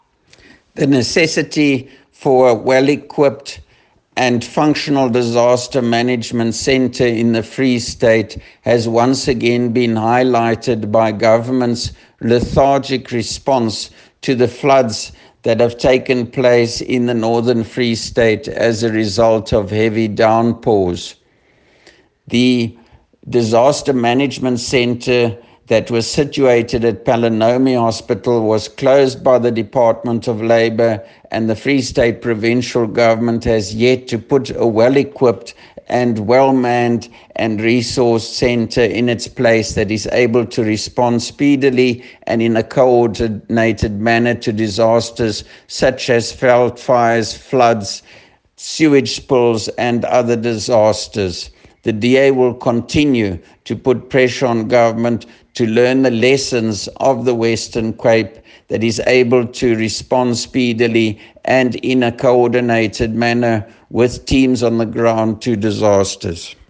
Afrikaans soundbites by Roy Jankielsohn MPL and